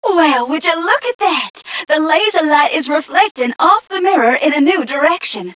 mission_voice_m4ca006.wav